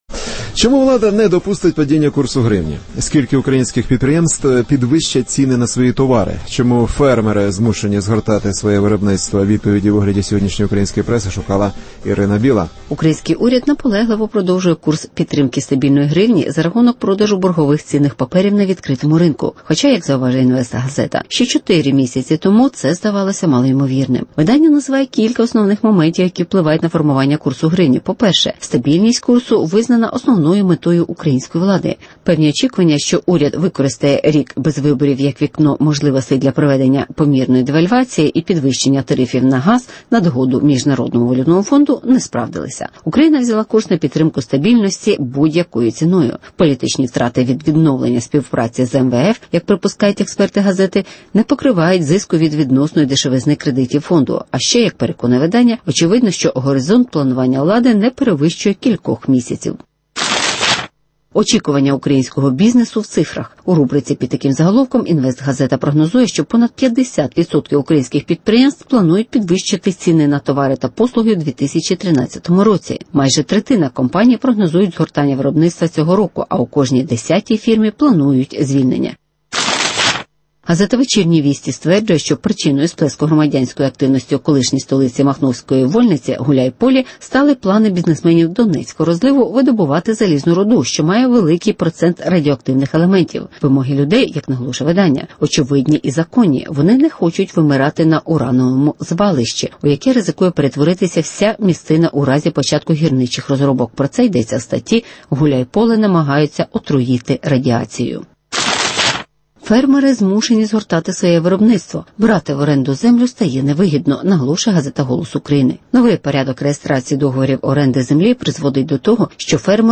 Якою ціною уряд утримує вартість гривні (огляд преси)